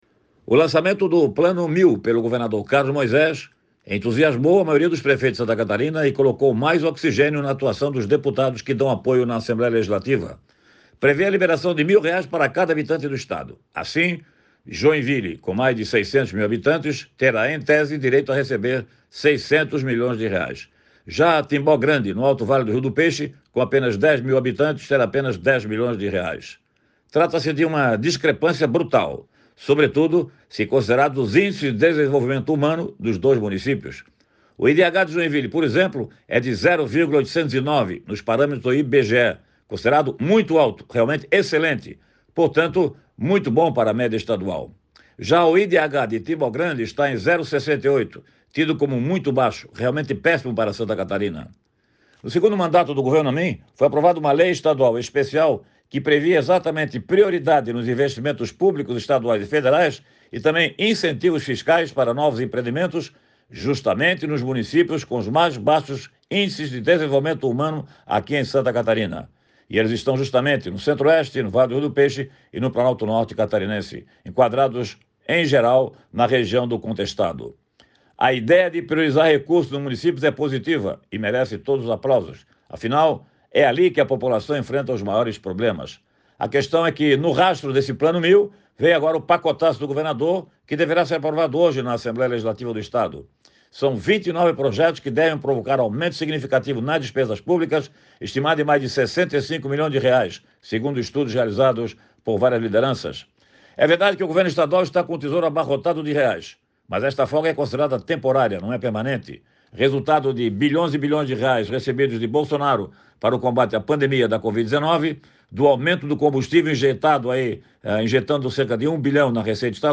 O jornalista destaca a reunião conjunta das três comissões técnicas da Alesc com projetos de investimento e lista de benefícios do funcionalismo público estadual